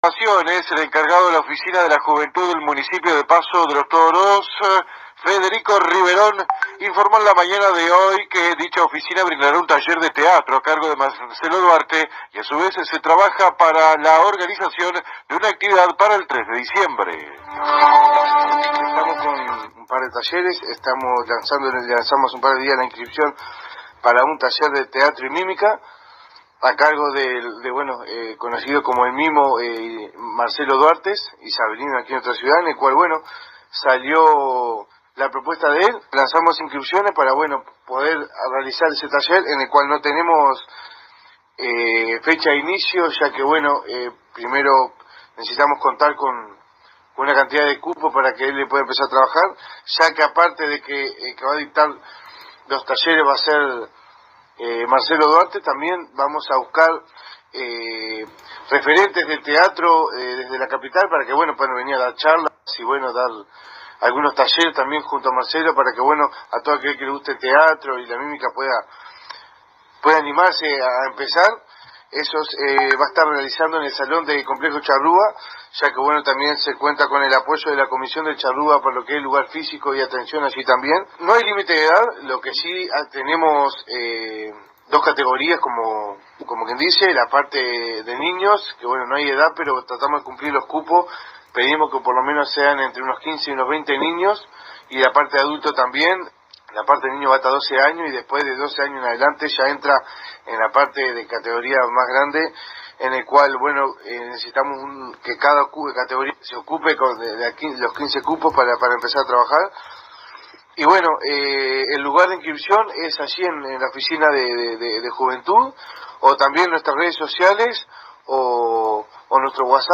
Fuente: AM 1110 Radio Paso de los Toros